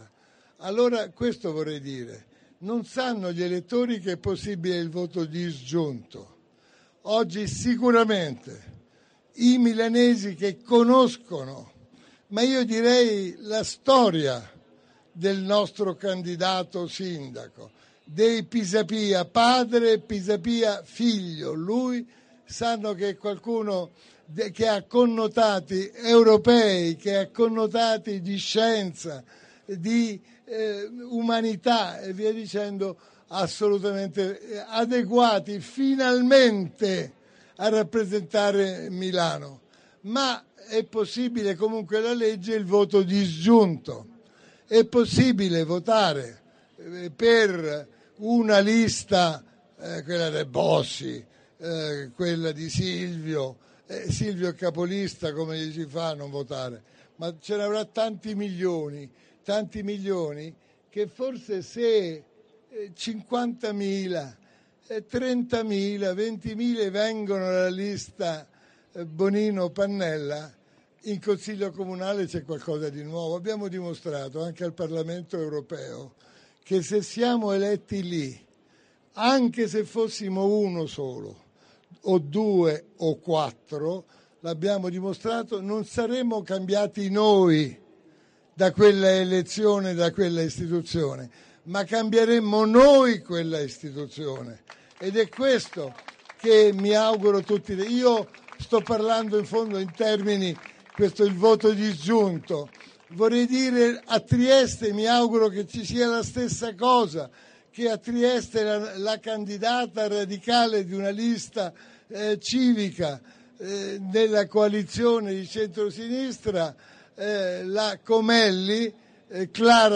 nel corso del comizio del 6 maggio scorso a Milano